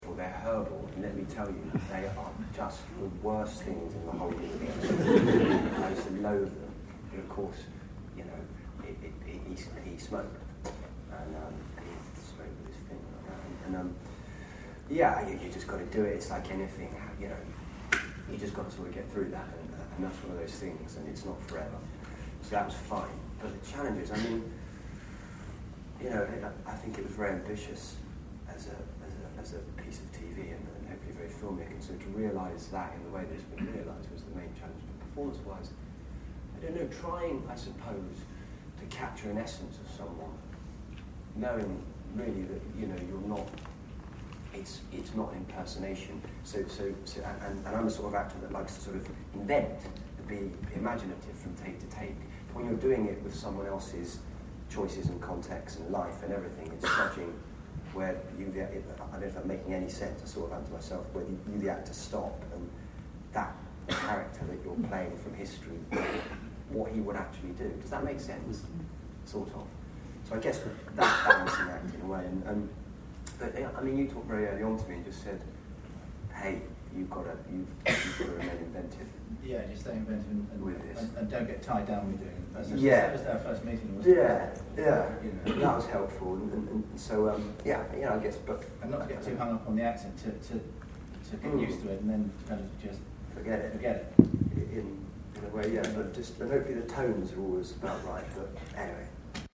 There was a Q&A after the screening with Matt, writer Kevin Elyot and director Geoffrey Sax.
Below are a few short audio extracts of what Matt had to say: